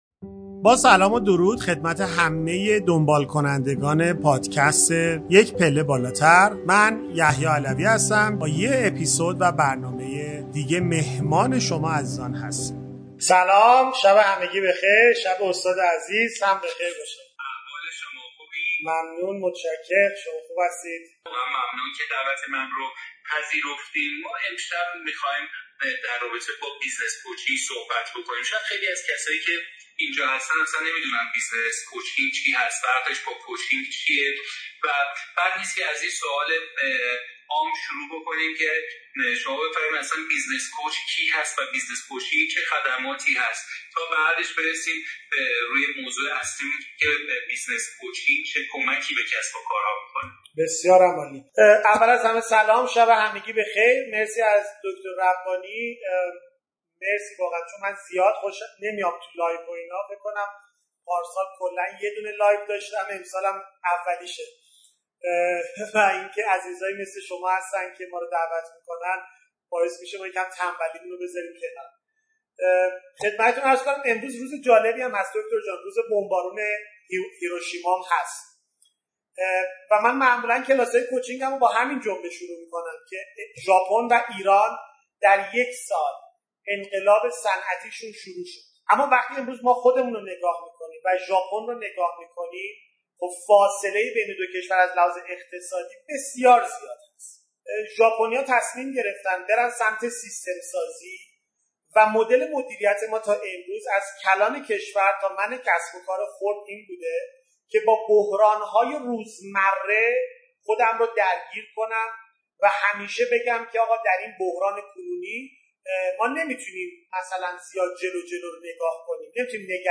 لایو مشترک